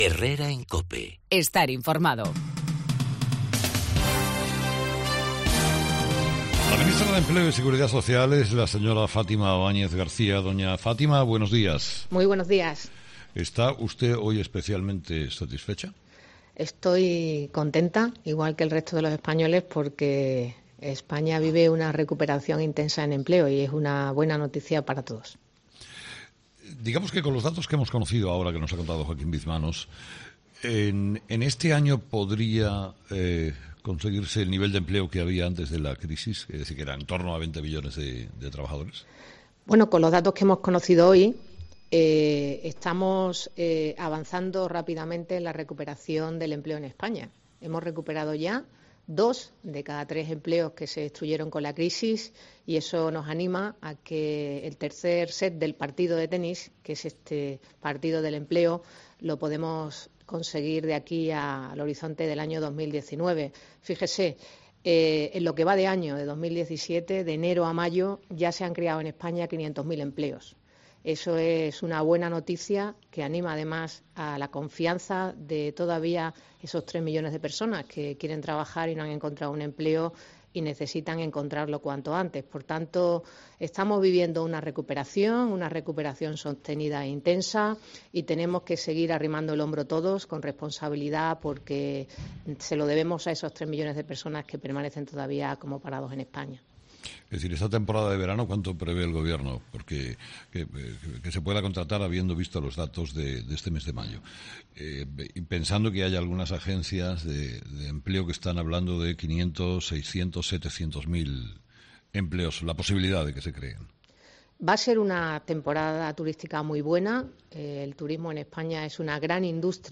Fátima Báñez, ministra de Empleo y Seguridad Social
ESCUCHA LA ENTREVISTA COMPLETA | Fátima Báñez en 'Herrera en COPE'